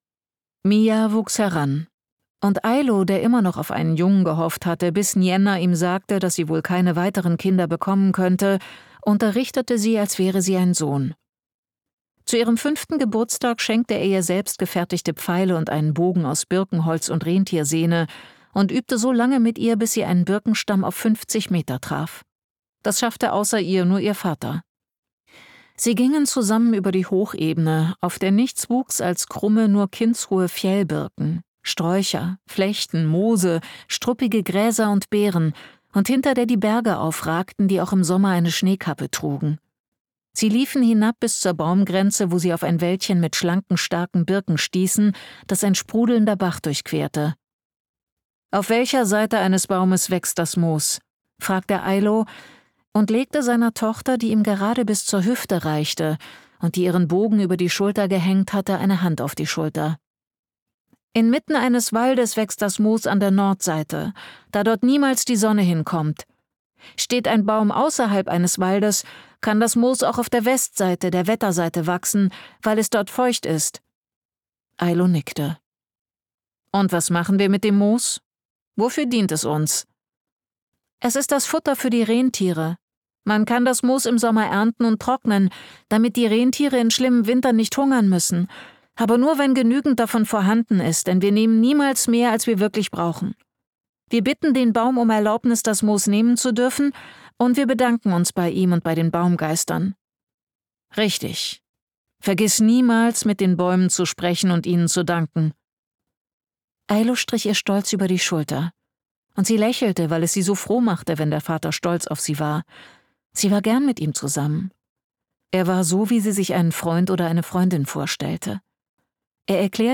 Die Heilerin des Nordens - Ines Thorn | argon hörbuch
Gekürzt Autorisierte, d.h. von Autor:innen und / oder Verlagen freigegebene, bearbeitete Fassung.